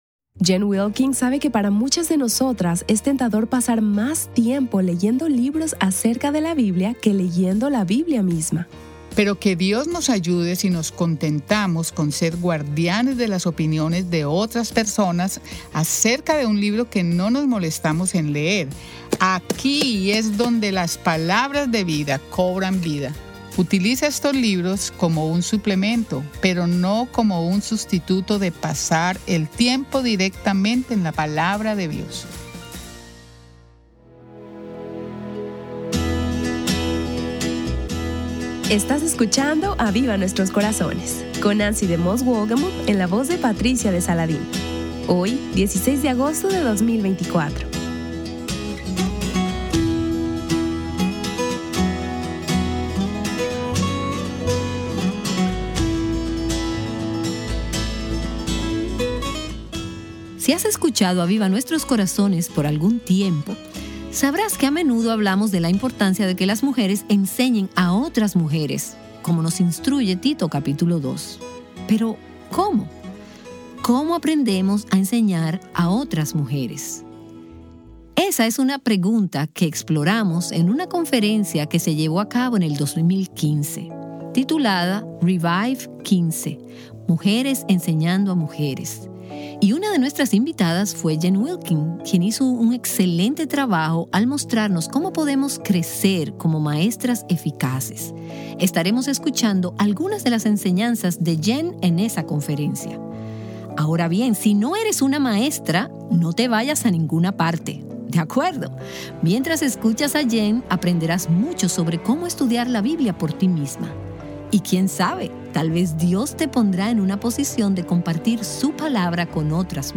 En el episodio de hoy, Jen Wilkin nos reta a obedecer el llamado de Dios de enseñar a otras mujeres la Palabra de Dios con temor y valentía, escucha la enseñanza.